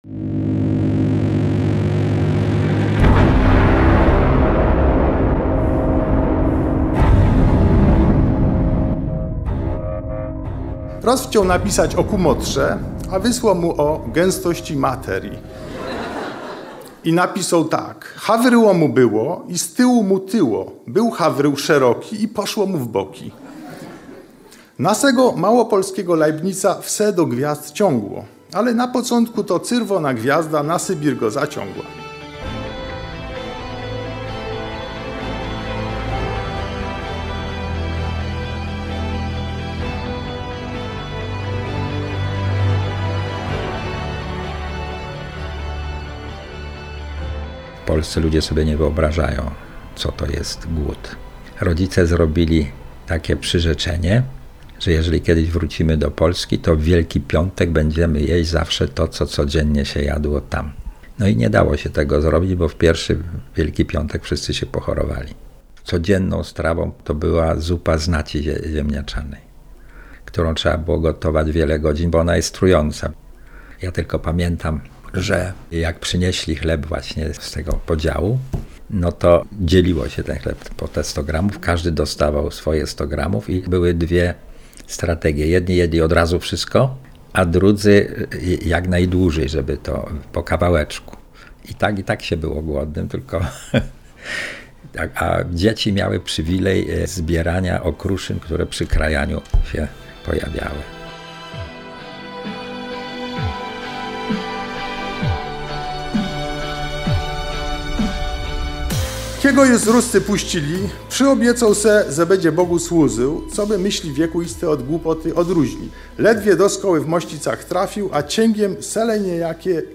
Reportaż w Radiu Kraków